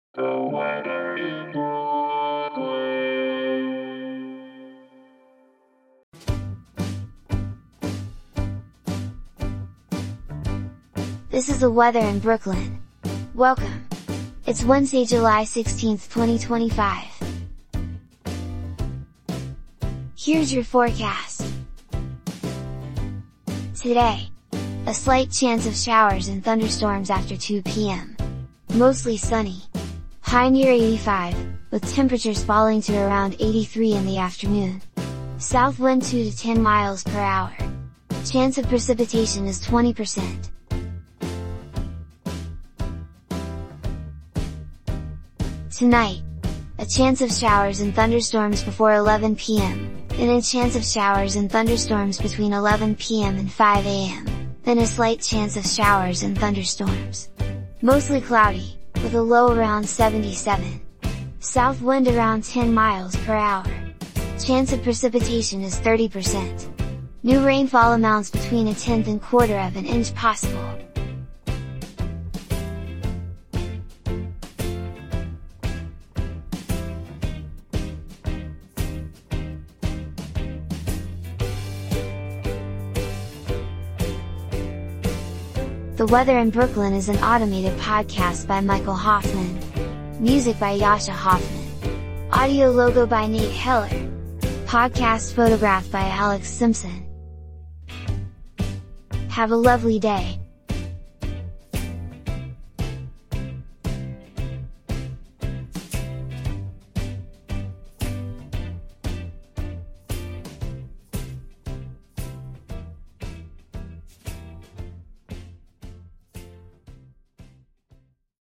and is generated automatically.